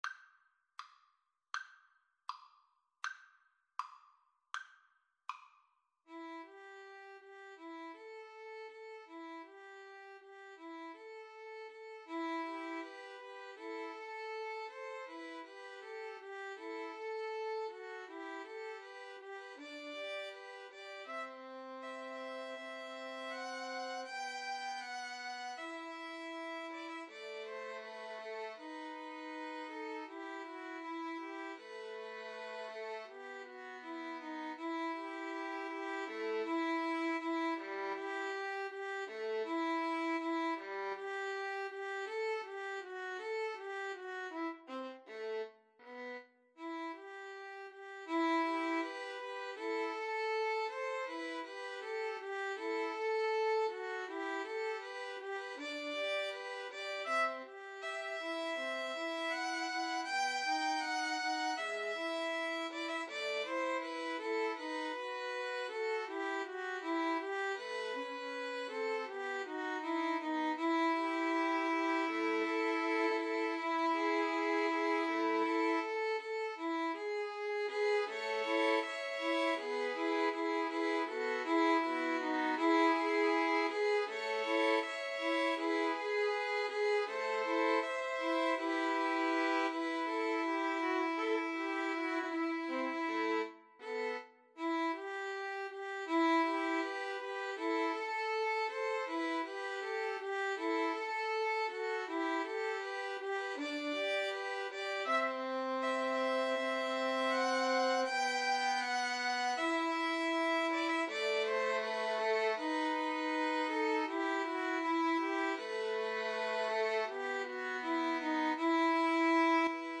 ~ = 100 Andante
Violin Trio  (View more Intermediate Violin Trio Music)
Classical (View more Classical Violin Trio Music)